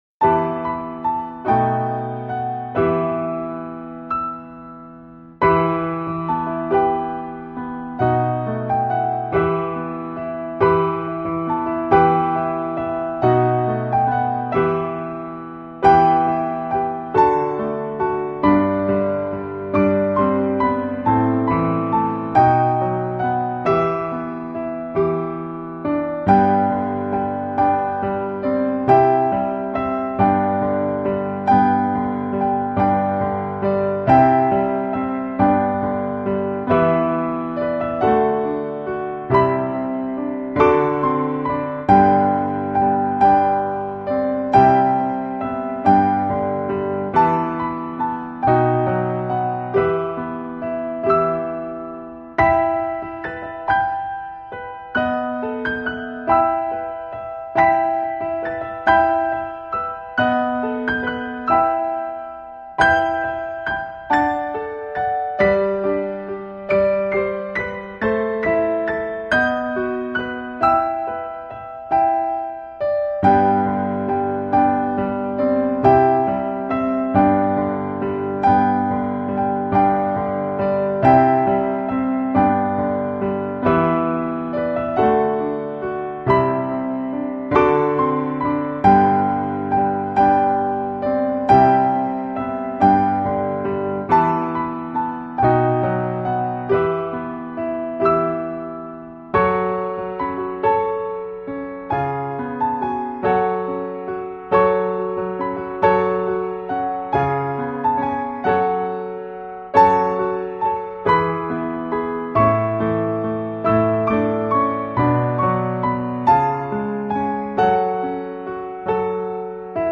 創新樂風，融合古典與現代的樂器與演奏，
所以他的音樂被列在New Age的型態裡，也列在Neo Class 的音樂型態裡。